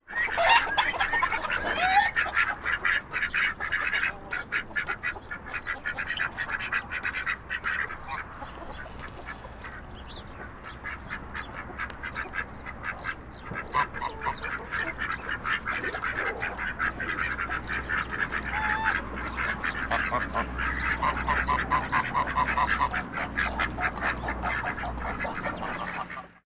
Ambiente de un gallinero normal